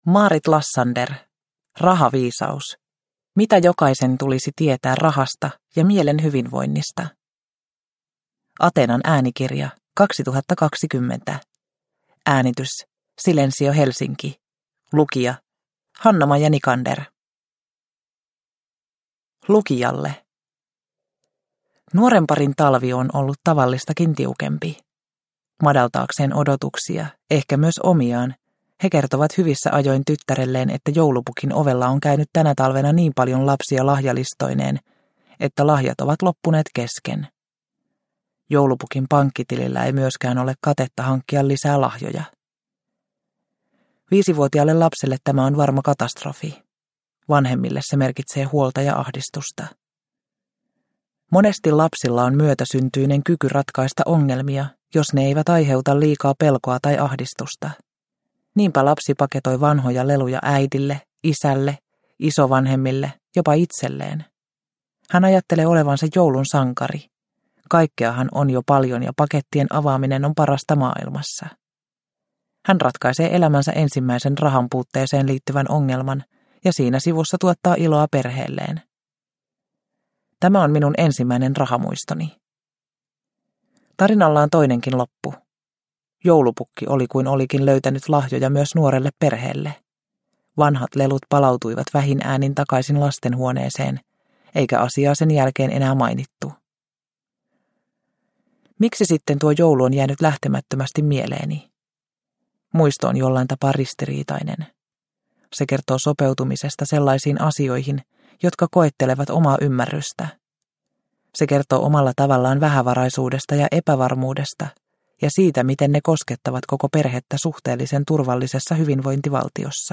Rahaviisaus – Ljudbok – Laddas ner